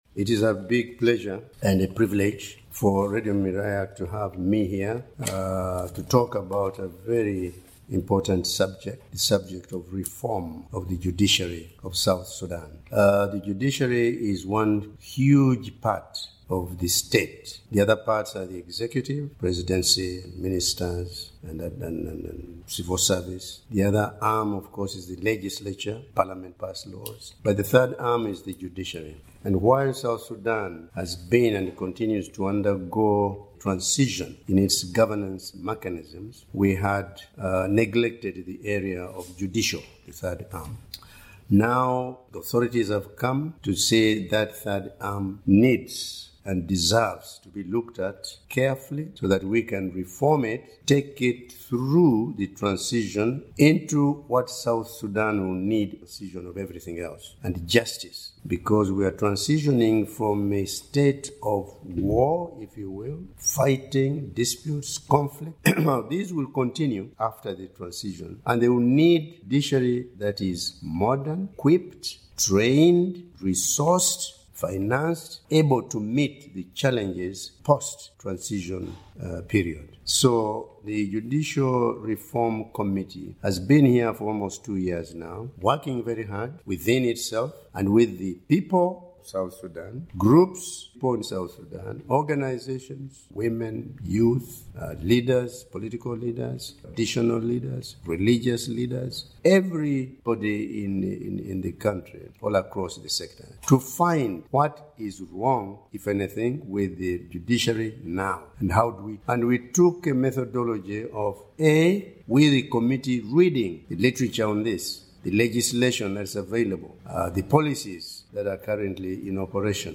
The Judicial Reform Committee has concluded its work and submitted its report, which includes recommendations for a robust reformation of South Sudan’s judiciary system. In this interview, the chairperson of the Judicial Reform Committee, Justice James Ogoola, says the implementation of the reforms is crucial as it reflects the aspirations of a broad range of South Sudanese communities.